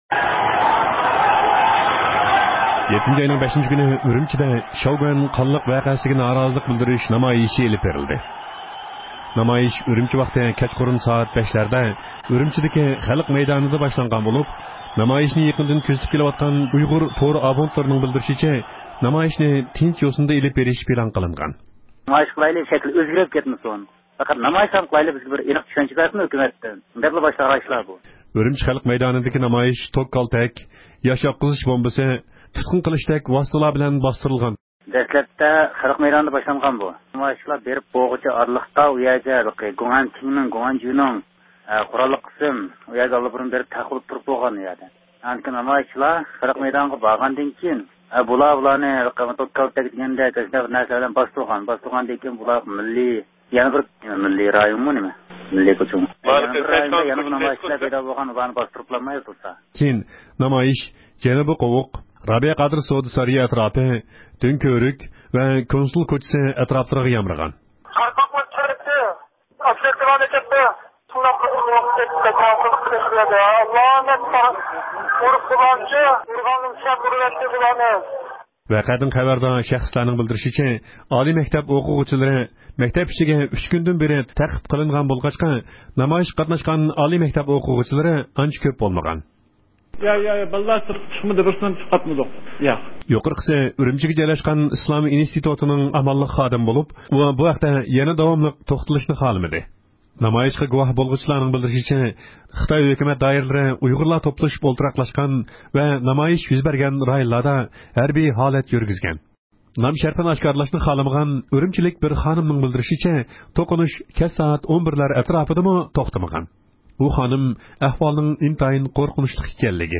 ئاڭلىماققا مەزكۇر ئايالنىڭ ئاۋازى ئىنتايىن ئەنسىز، كەيپىياتى ئىنتايىن جىددىي ئۆزى ۋەھىمىگە پاتقان ئىدى.